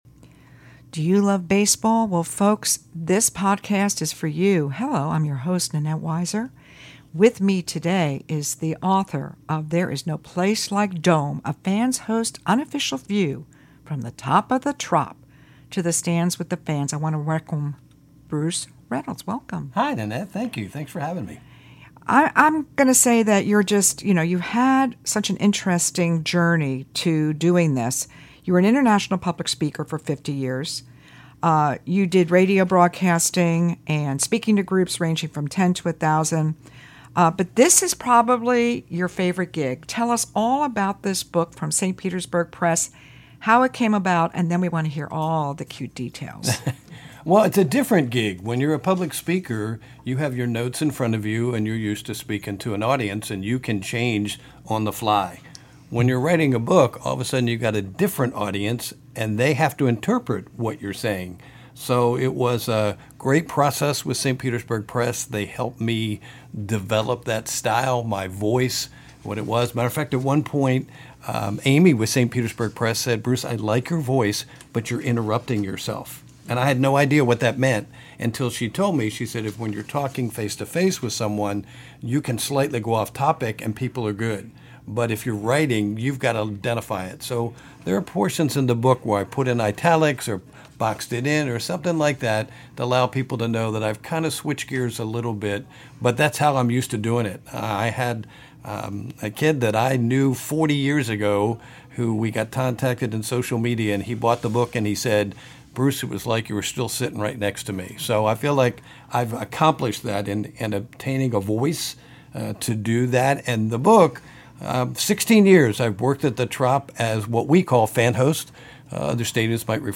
wonderful conversation